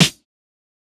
TS Snare_12.wav